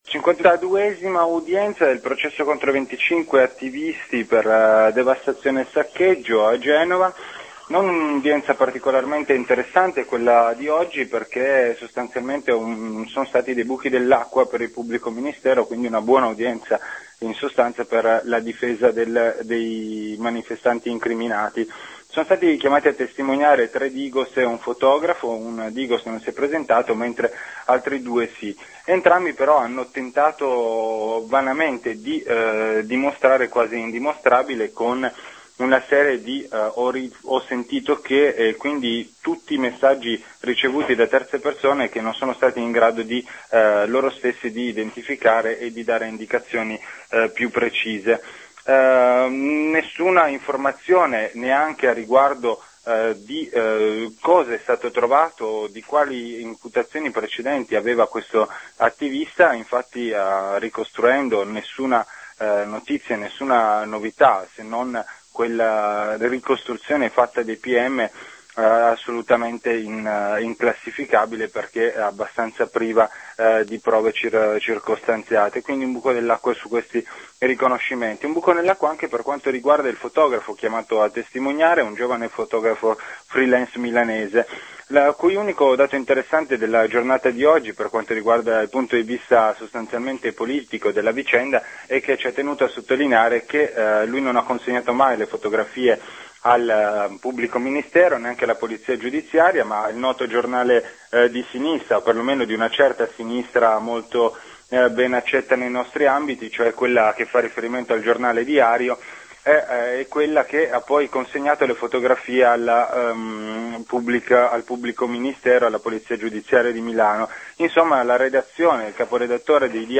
Intervista sullla cinquantaduesima udienza del processo per devastazione e saccheggio durante il g8 2001 a 25 manifestanti e sui nuovi avvisi di fine indagine